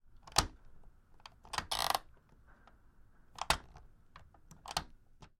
抽象的声音 " 声波环境3
描述：一种起伏的音景，有节奏的吱吱声和柔和的金属共鸣，源自操纵的现场录音和铃声。
标签： 金属 大气 声景 抽象的 吱吱作响的
声道立体声